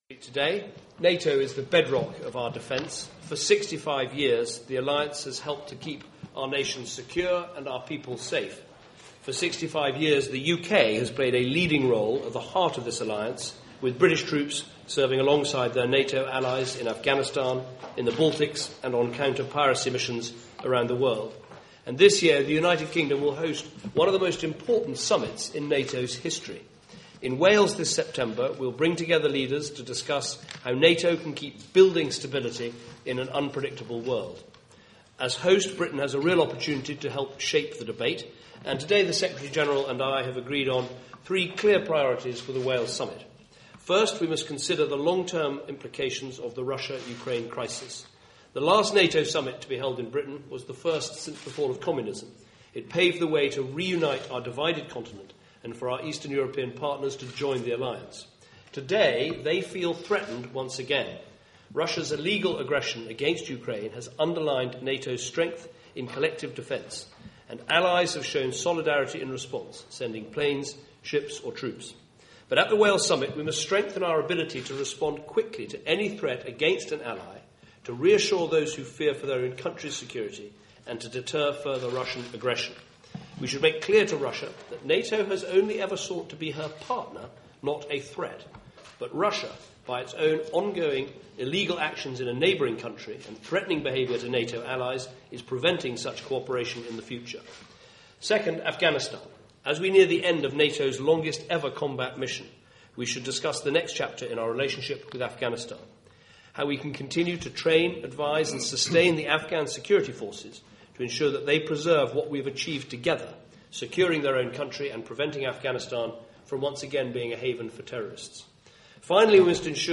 Future NATO - Speech by NATO Secretary General Anders Fogh Rasmussen at Chatham House - London, United Kingdom